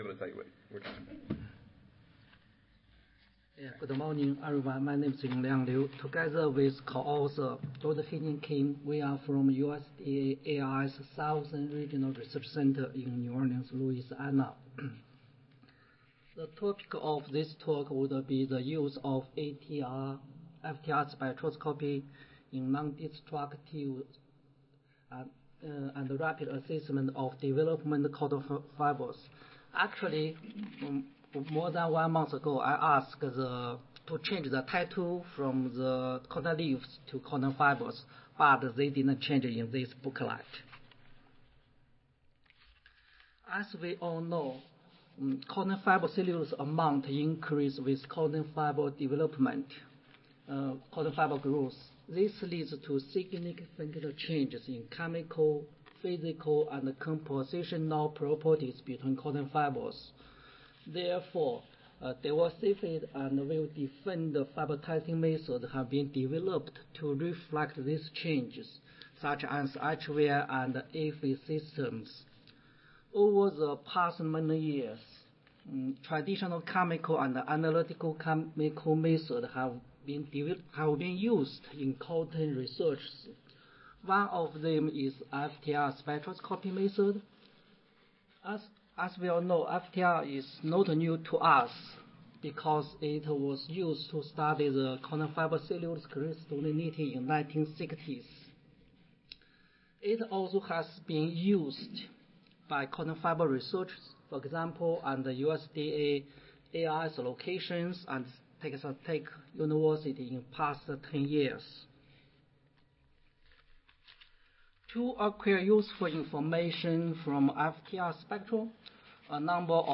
Southern Regional Research Center Audio File Recorded Presentation